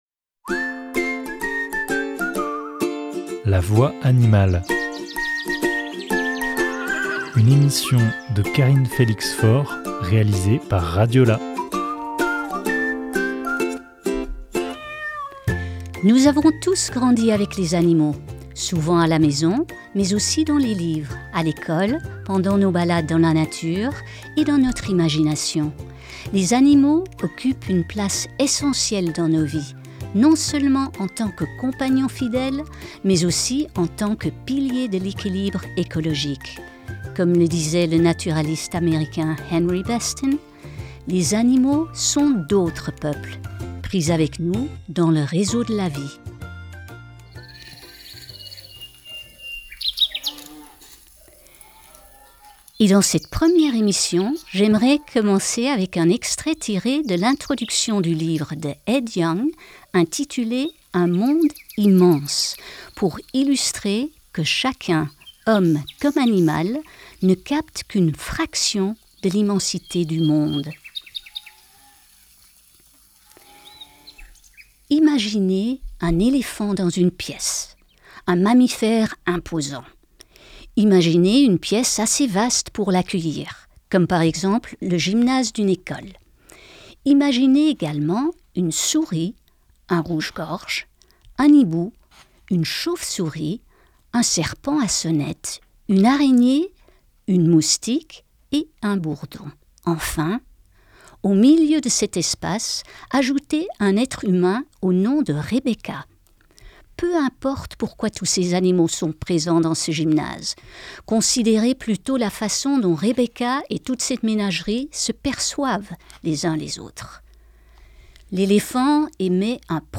Découvrez chaque mois une espèce ou un concept animalier, à travers des anecdotes, des lectures, des interviews d’experts et des choix musicaux.